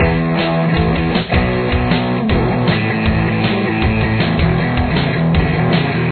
Main Riff
Guitar 1